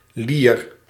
Ääntäminen
Ääntäminen Tuntematon aksentti: IPA: /lir/ IPA: [liːr] IPA: [liə̯r] Haettu sana löytyi näillä lähdekielillä: hollanti Käännös Konteksti Ääninäyte Substantiivit 1. winch 2. lyre 3. shell musiikki US Suku: f .